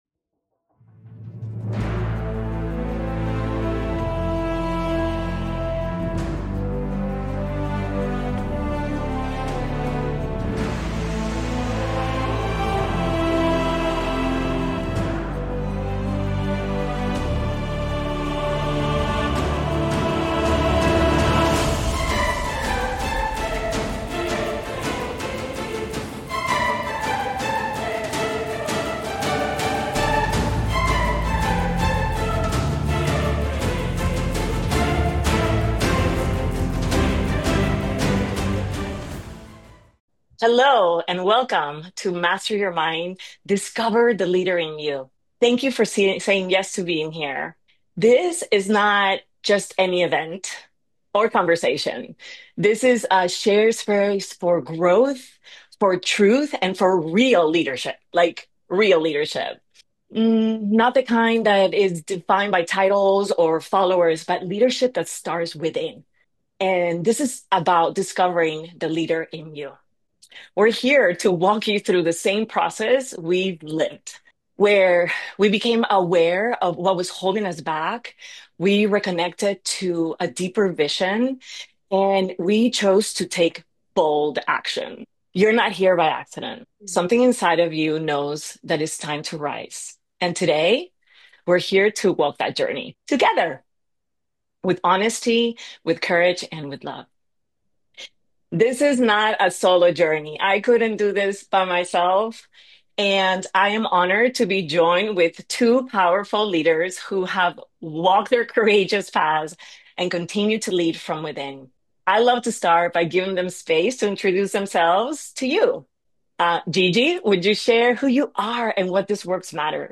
Each episode dives into real conversations on emotional intelligence, self-discipline, confidence, and mindset mastery.